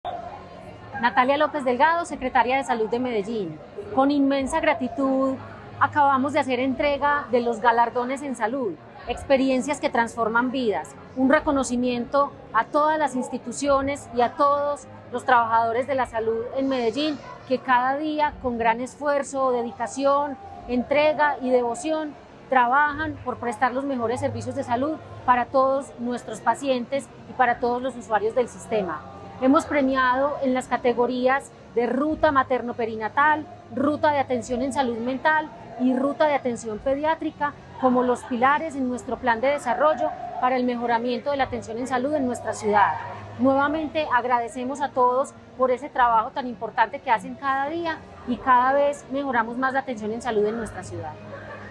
Declaraciones de la secretaria de Salud, Natalia López Delgado En medio de la crisis nacional del sector salud, la Alcaldía de Medellín reafirma su compromiso con la vida.
Declaraciones-de-la-secretaria-de-Salud-Natalia-Lopez-Delgado.mp3